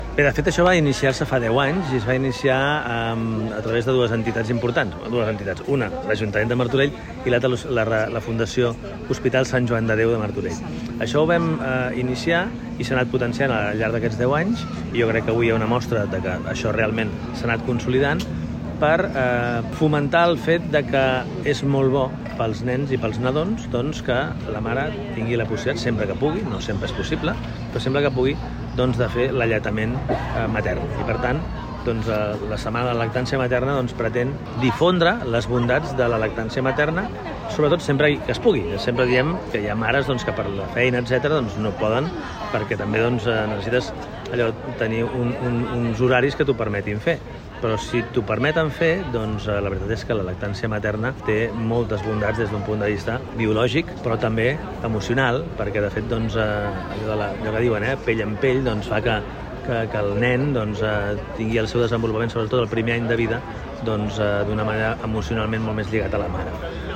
Aquest matí s’ha fet a la plaça de Les Cultures de Martorell la inauguració de la 10a Setmana de la Lactància Materna, que tindrà lloc del 20 al 24 d’octubre a diferents poblacions del Baix Llobregat Nord, entre elles Martorell, amb ponències i activitats per promoure l’alletament.
Xavier Fonollosa, alcalde de Martorell